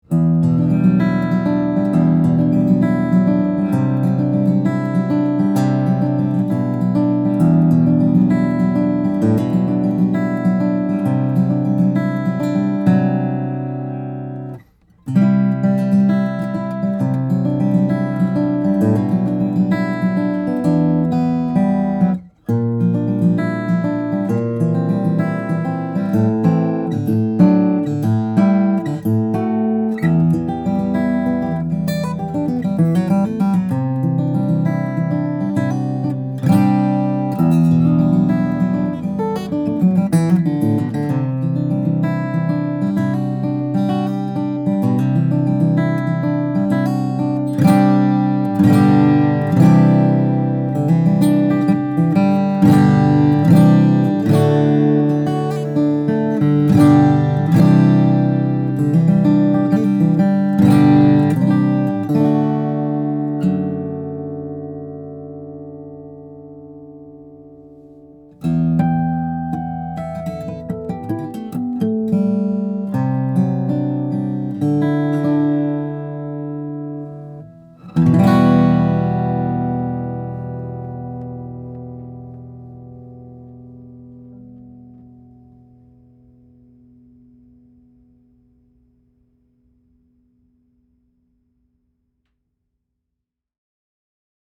2019 Ryan Signature Series Nightingale, Brazilian Rosewood/Sinker Redwood - Dream Guitars
Pure velvet.
Very rich and expressive, with an added blooming quality in the bass response that’s particularly sweet to experience.